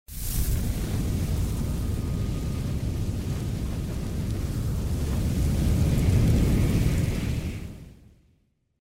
flamethrower-sound.mp3